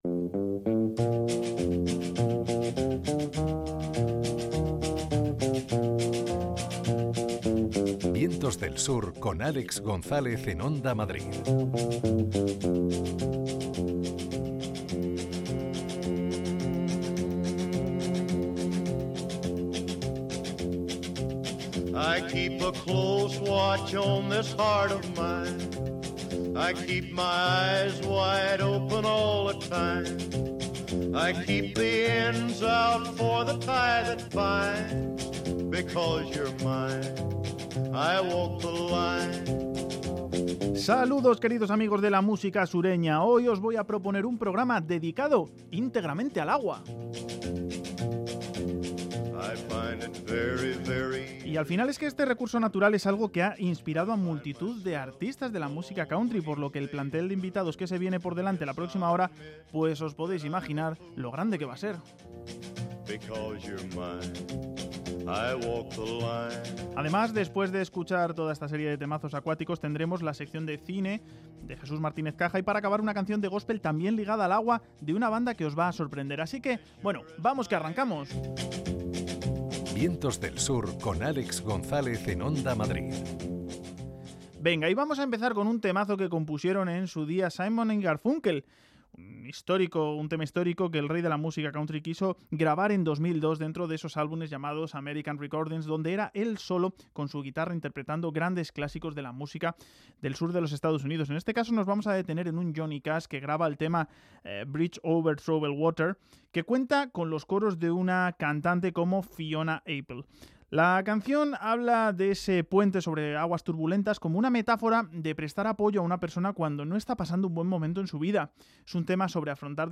Programa dedicado a la relación de la música country con el agua.